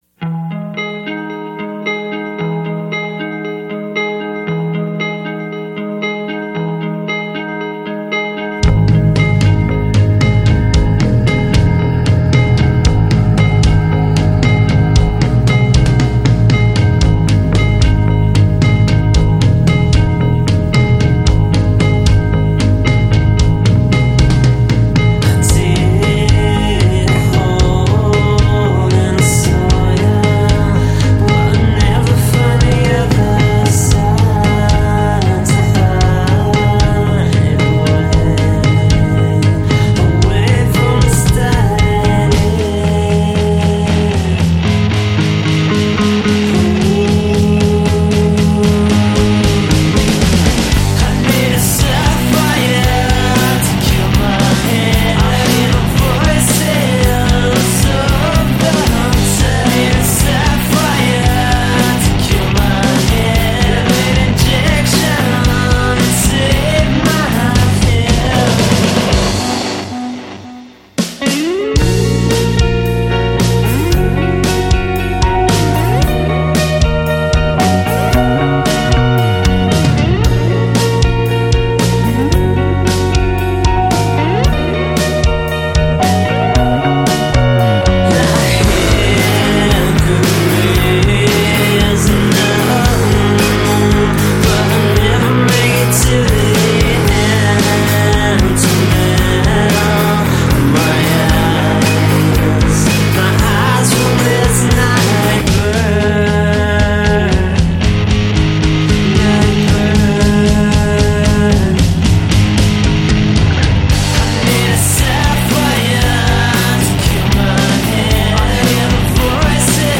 indie/alt-rock
a horror themed collection of noisy alt-rock.